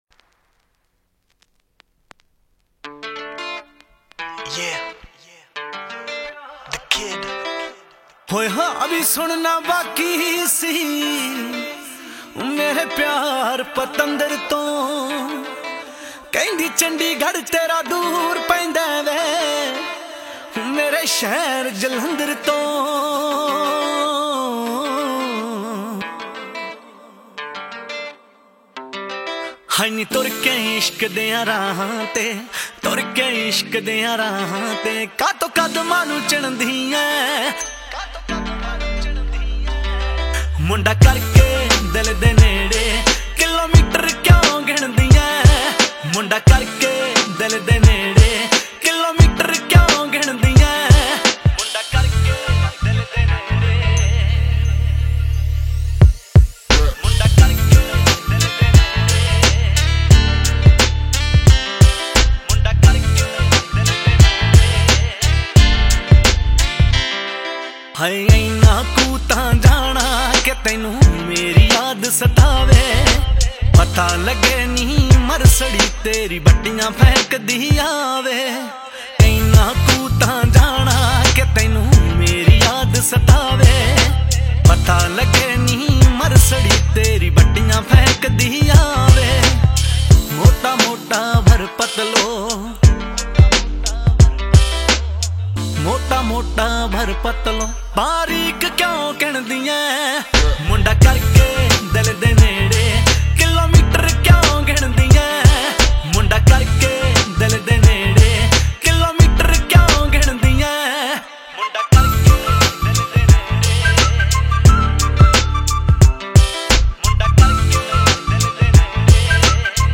Punjabi Bhangra
Indian Pop